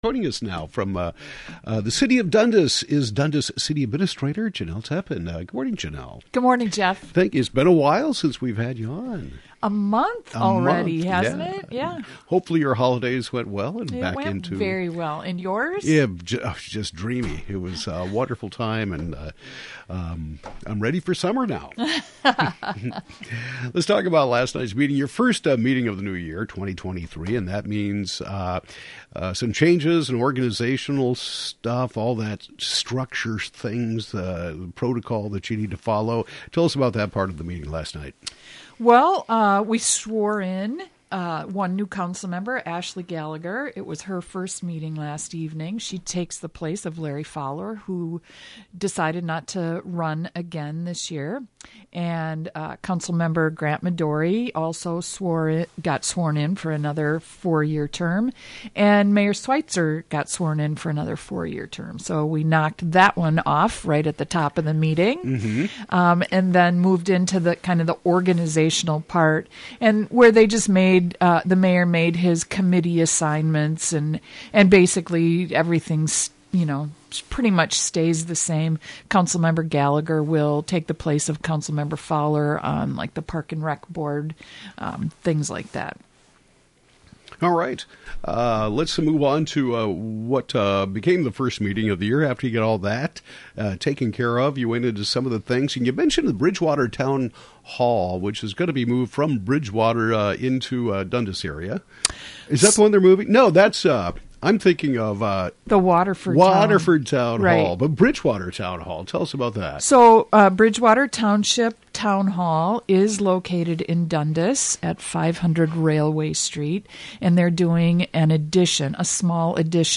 Dundas City Administrator Jenelle Teppen reviews the January 9 City Council meeting. Topics include plans for an addition to Bridgewater Town Hall, the hiring of two full-time police officers, and more.